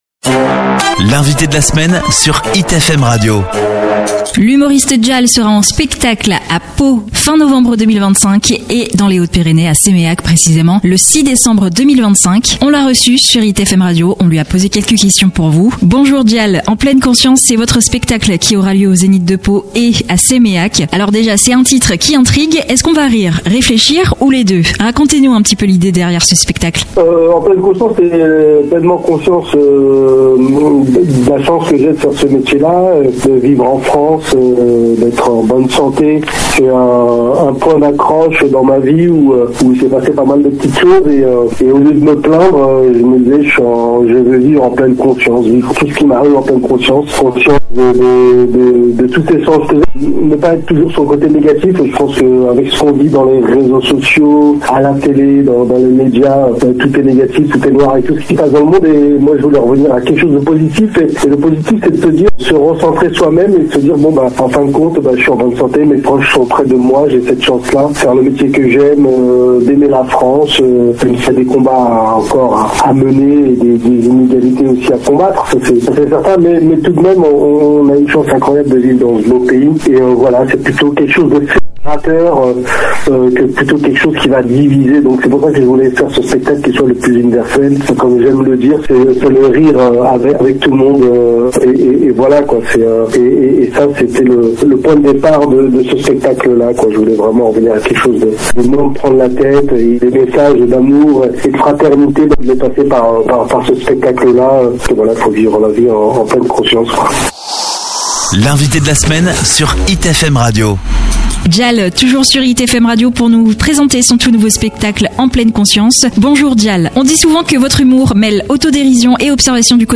Cette semaine, Hit FM Radio recevait D’Jal, venu présenter son nouveau spectacle En Pleine Conscience, qu’il jouera au Centre Léo Lagrange de Séméac le 6 décembre, puis à Agen Agora en février 2026.
Avec son énergie contagieuse et son sens inné de la narration, l’humoriste nous a expliqué la philosophie de ce titre intrigant : « En pleine conscience », c’est sa façon d’aborder la vie, d’observer le monde qui l’entoure et d’en faire jaillir un humour plus authentique que jamais.